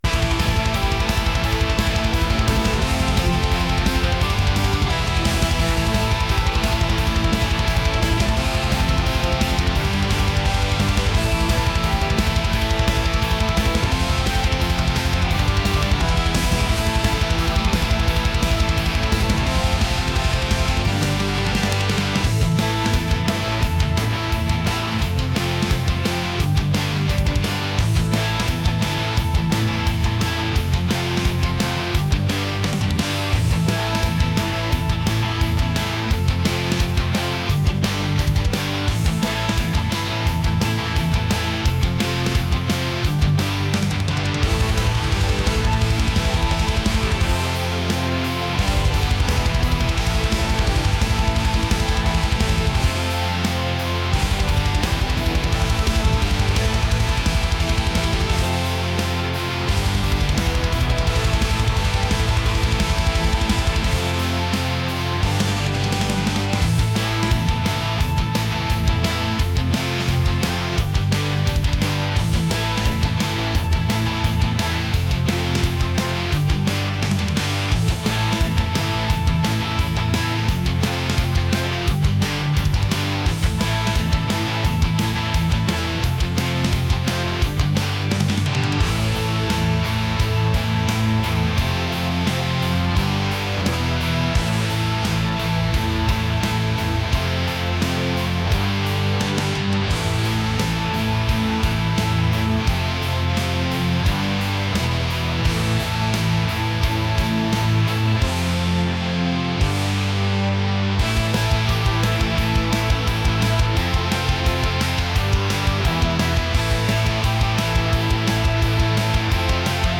heavy | metal